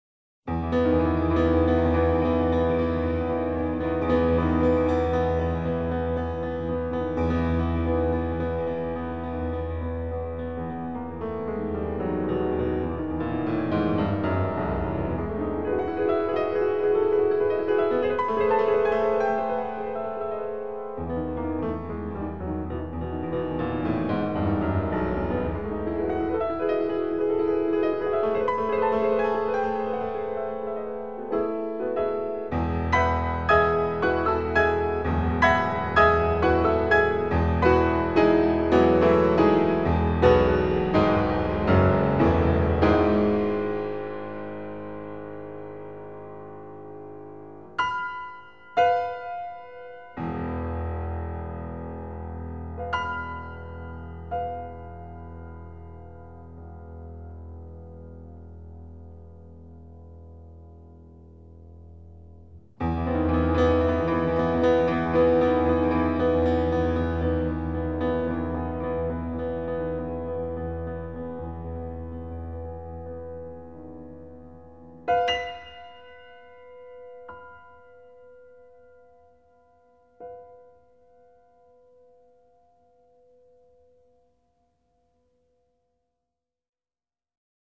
鋼琴協奏曲